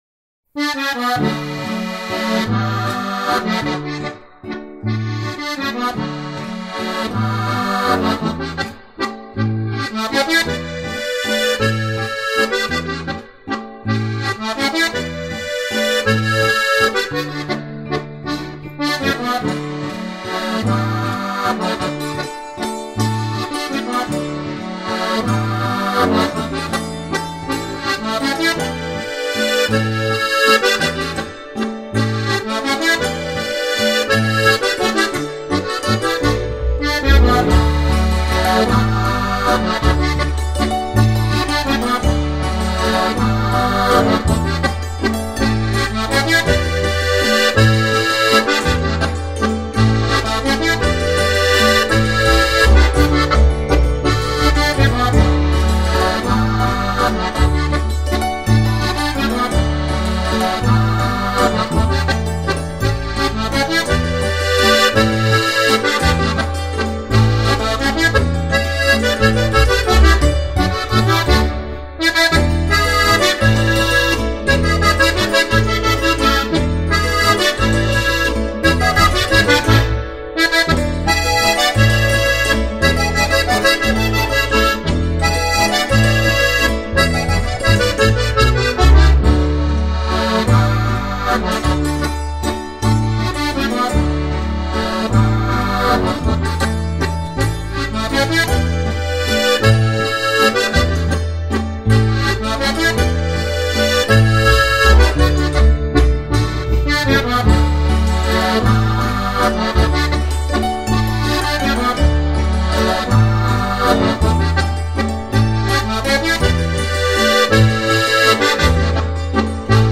Ein gemütlicher Volksmusik-Walzer aus dem schönen “Kärnten!
• 4-Reihige Harmonika